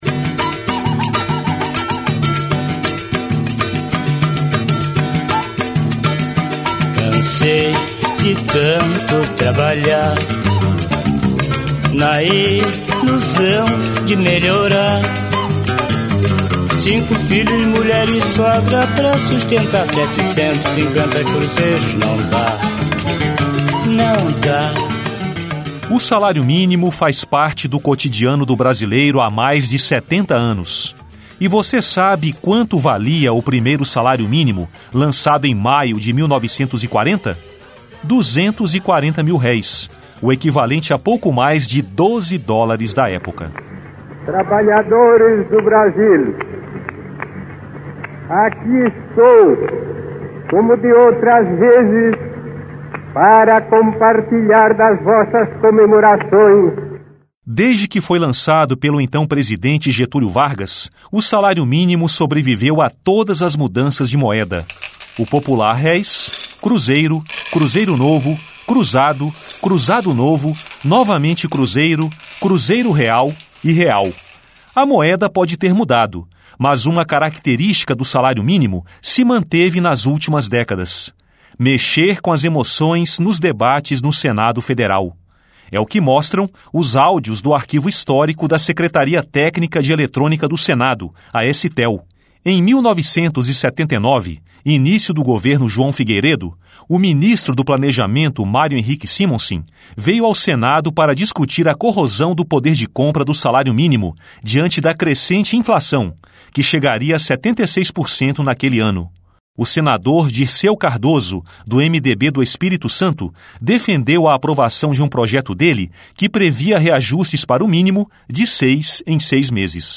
É o que mostram os áudios do arquivo histórico da Secretaria Técnica de Eletrônica do Senado, a STEL.